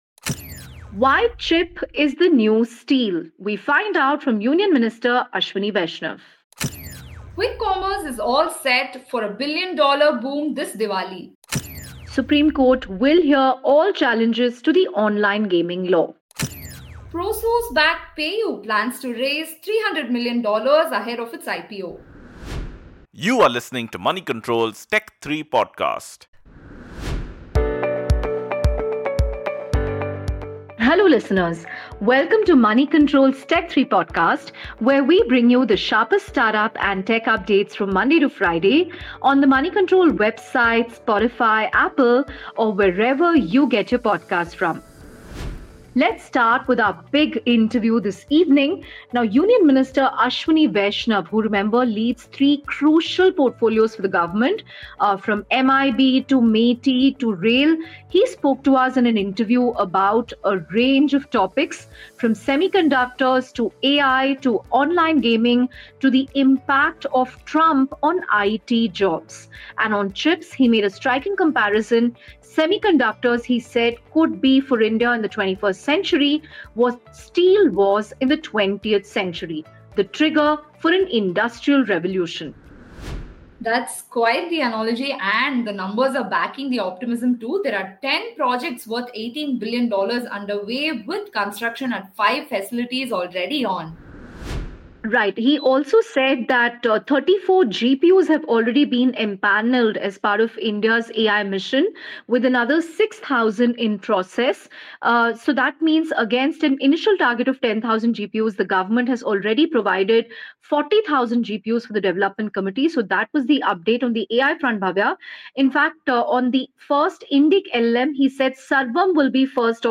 In today’s Tech3 from Moneycontrol, Union Minister Ashwini Vaishnaw tells us why semiconductors could be India’s steel of the 21st century and shares updates on GPUs, AI, and online gaming act. We also track the billion-order race in quick commerce this Diwali, as platforms scale up beyond groceries. Plus, the Supreme Court takes charge of petitions challenging the Online Gaming Act, and PayU readies a $300 million fundraise ahead of its planned IPO.